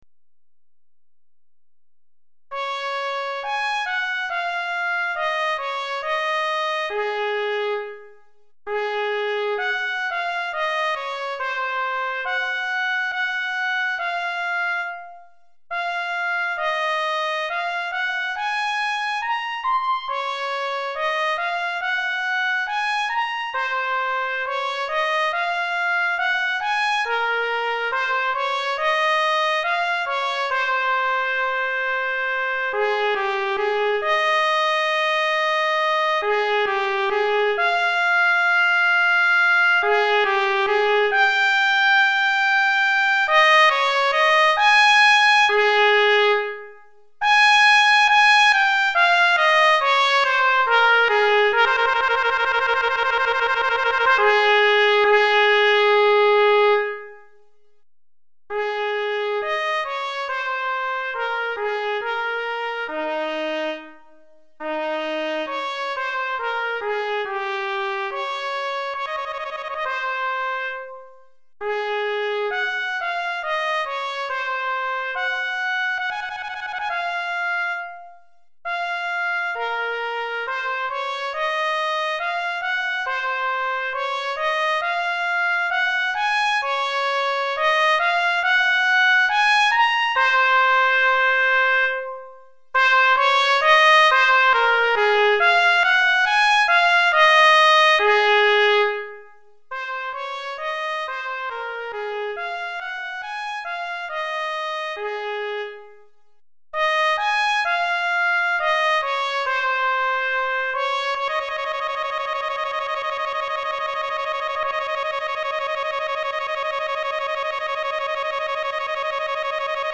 Trompette Solo